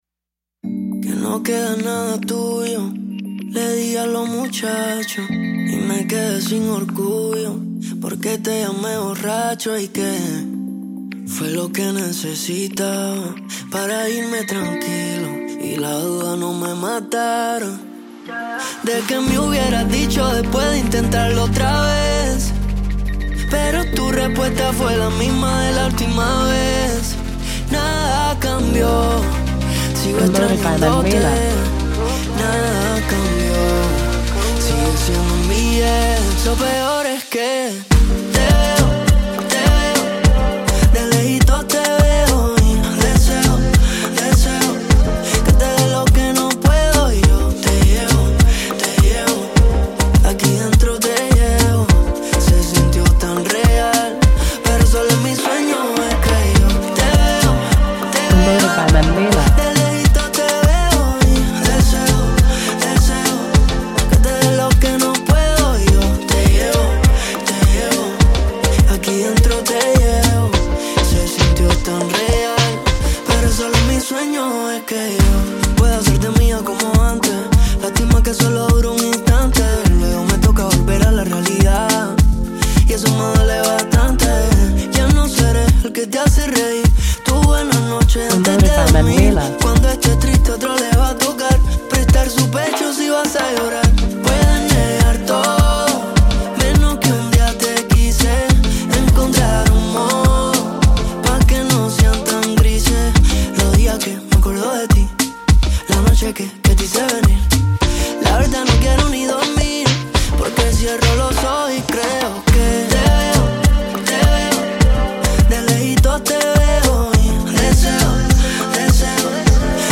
Radio Edit
pop latine